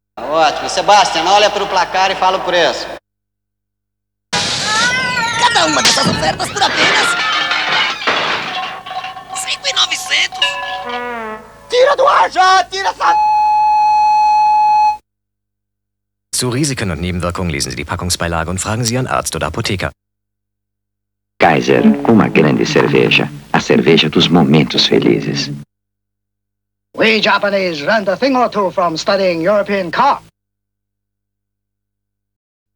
37 TV _ Commercials.wav